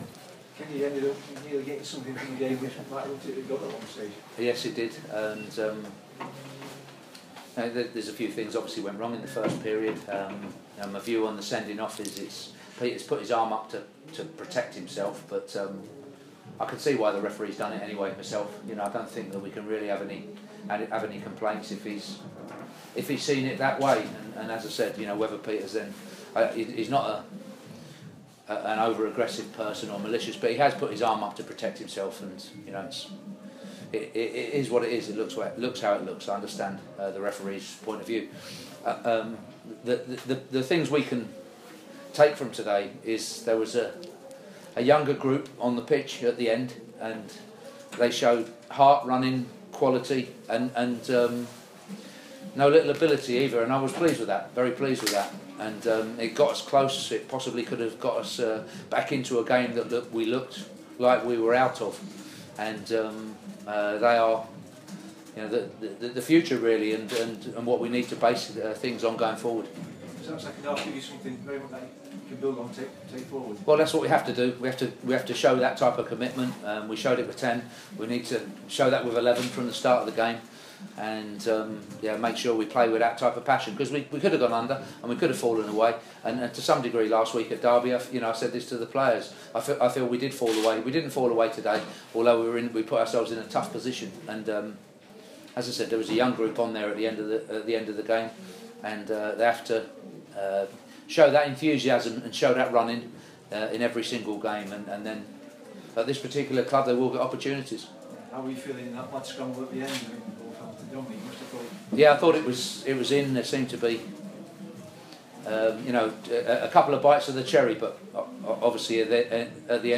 Kenny Jackett speaks to the press folloiwng Rotherham's narrow 2-1 defeat to Leeds.